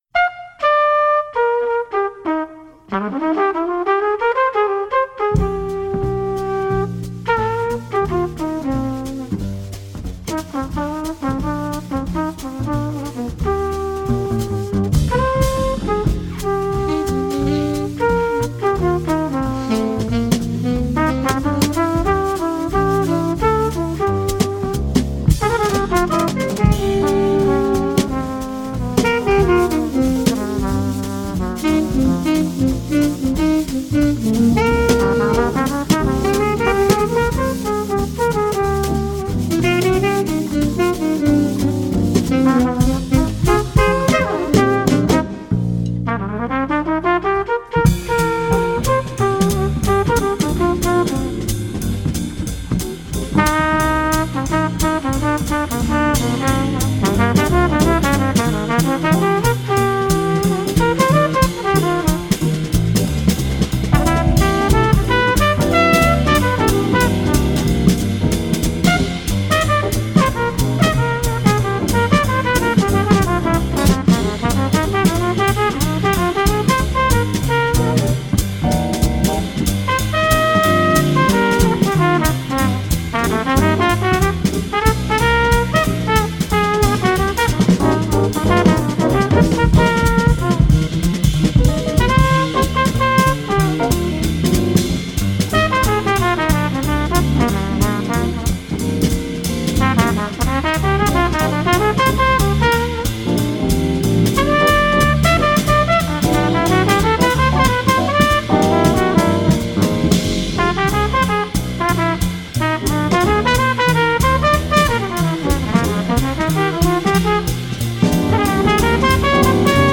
Tangerine Jam: An Eclectic Ode In Jazz
Here’s one of my old Dinner Music/Cocktail music mashups. It’s not stuffy and it’s not boring if you like jazz and dabble in hip hop, that is.